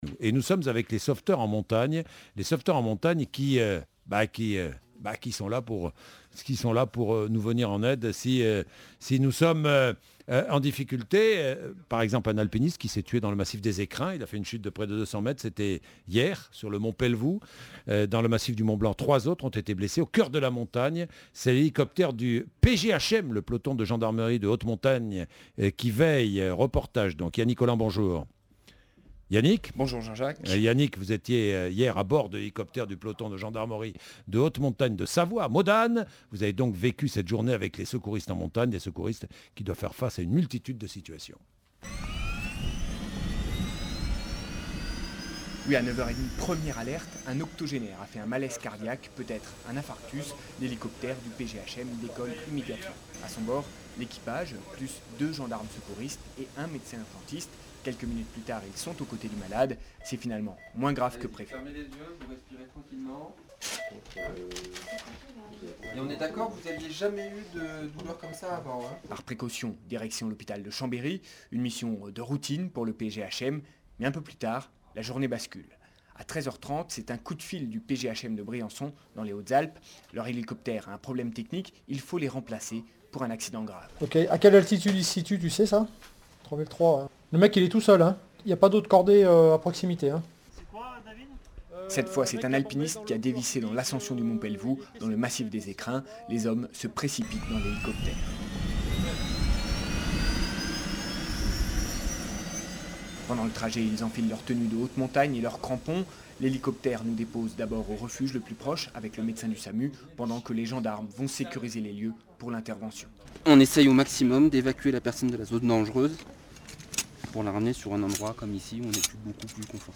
Reportage avec le PGHM de Modane en Savoie.